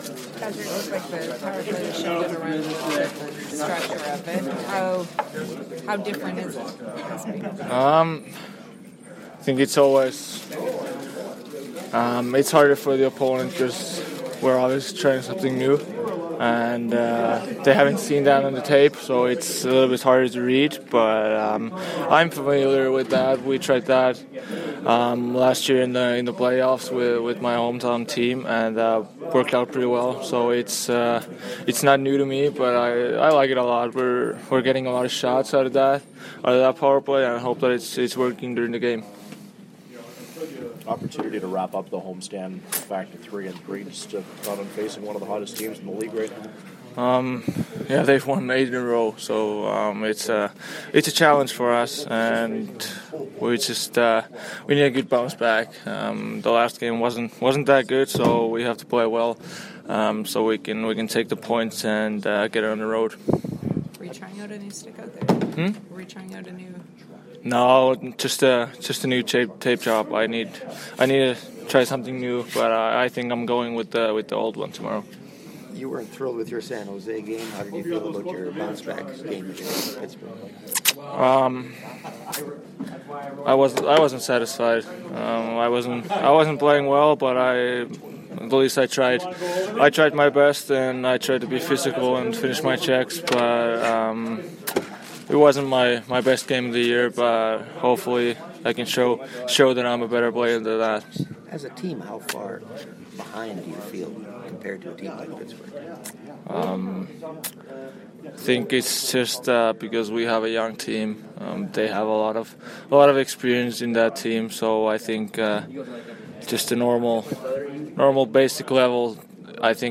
Latest from the Jets skate at the MTS Centre.